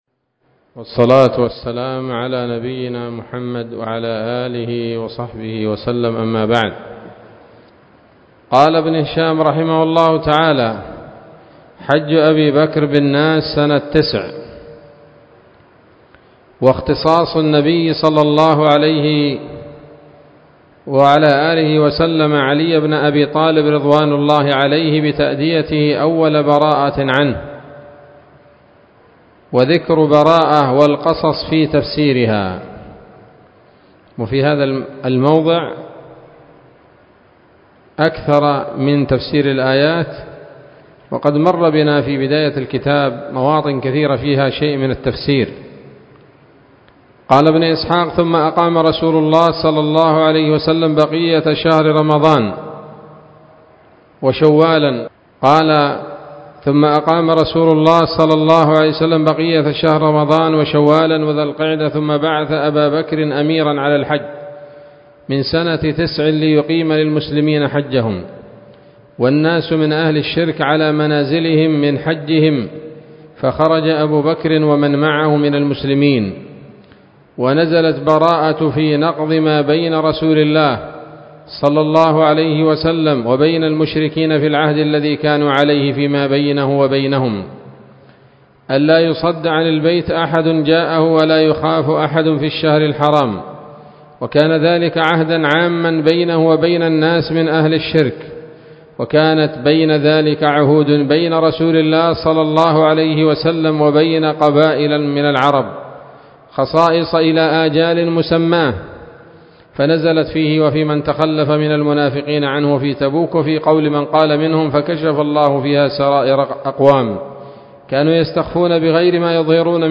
الدرس السادس والثمانون بعد المائتين من التعليق على كتاب السيرة النبوية لابن هشام